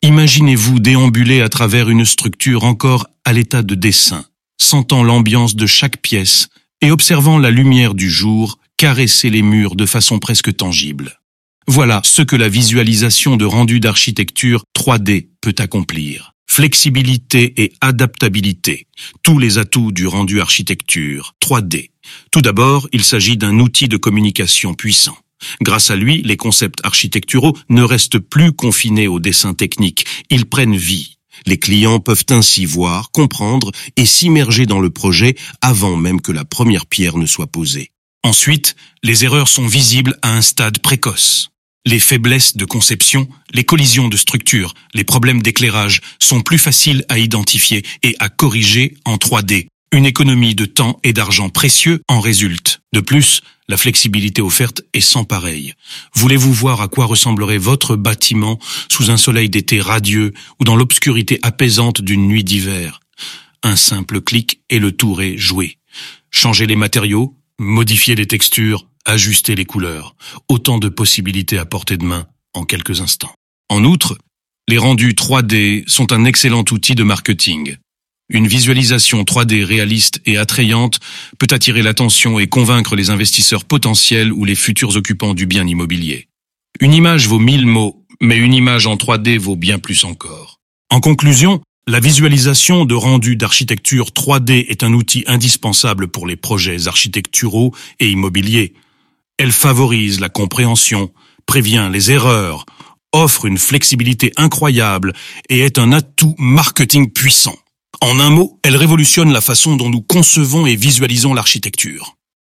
HARRIS-STUDIO-Architecture-3D-version-vocale.mp3